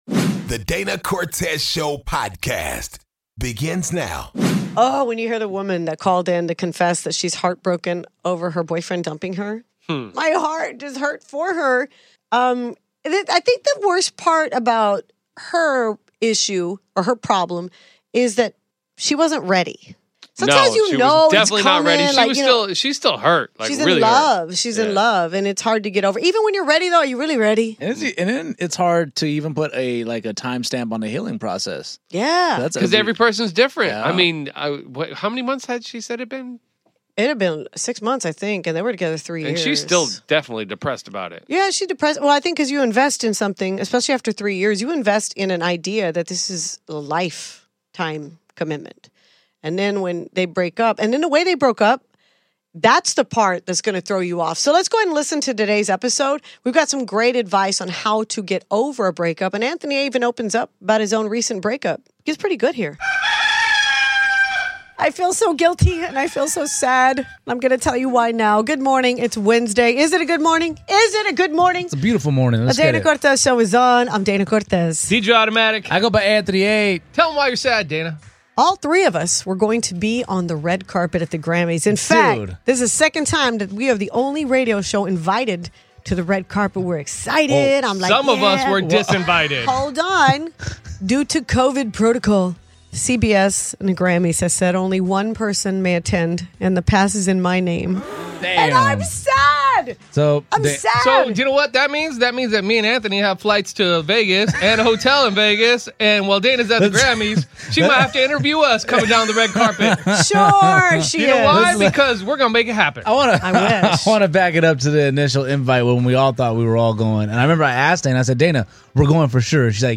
DCS got a call from a listener who was recently "dumped" by her boyfriend. Saying she is heartbroken is an understatement so we gave her five tips on how to get over it. Plus DCS talks "Skante", Harvard marijuana studies and habits that lead to break ups.